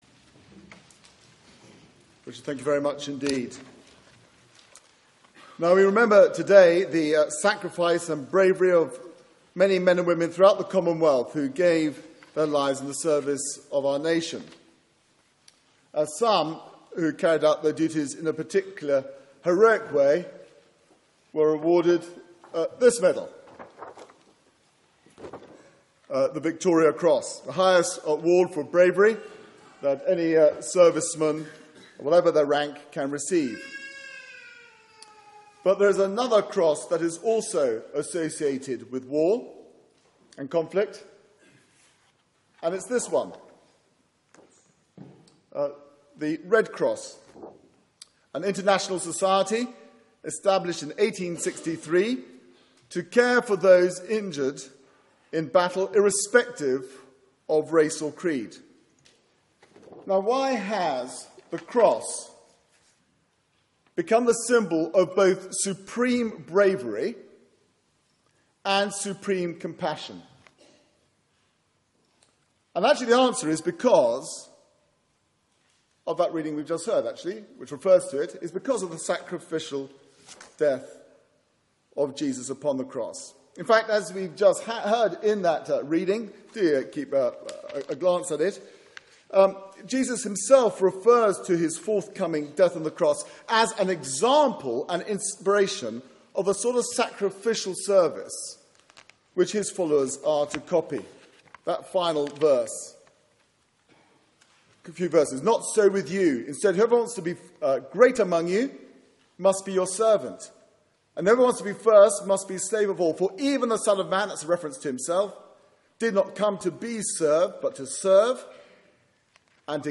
Media for 9:15am Service on Sun 09th Nov 2014 10:50 Speaker
Passage: Mark 10:35-45 Series: Remembrance Service Theme: Sermon